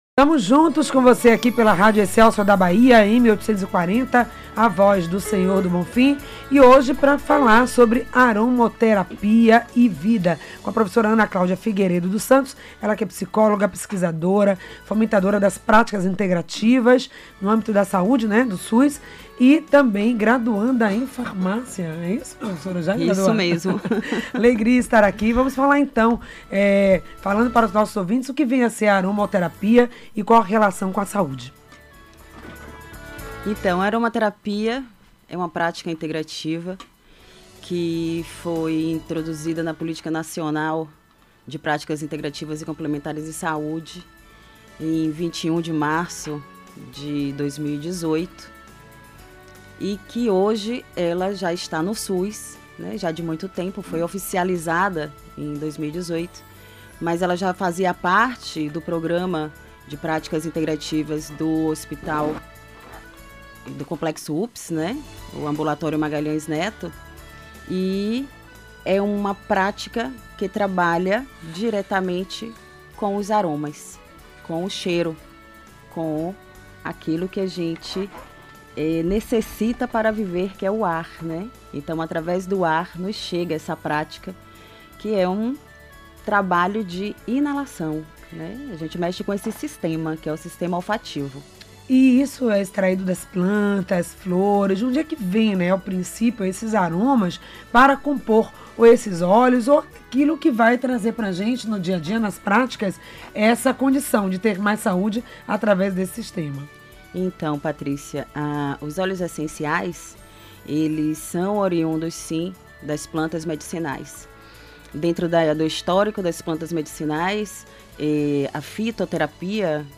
Aromoterapia.mp3